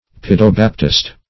Search Result for " paedobaptist" : The Collaborative International Dictionary of English v.0.48: Pedobaptist \Pe`do*bap"tist\, n. One who advocates or practices infant baptism.